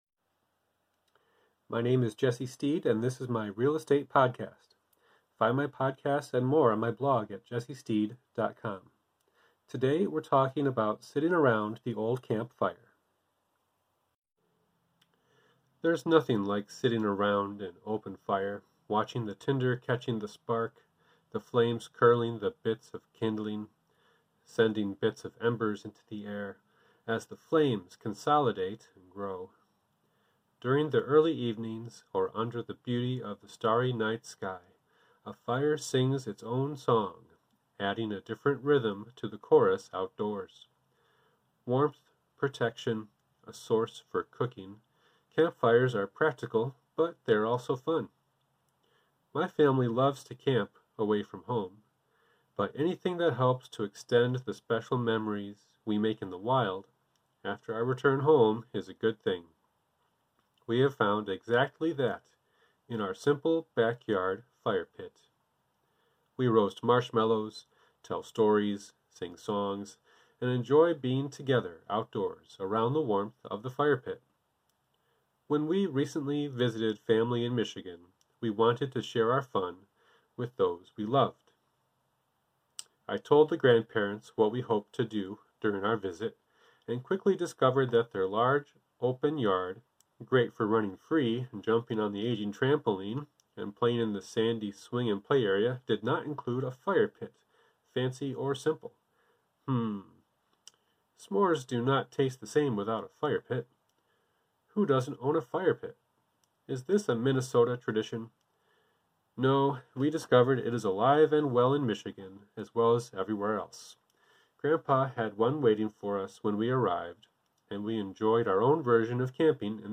During the early evening or under the beauty of the starry night sky, a fire sings its own song adding a different rhythm to the chorus outdoors.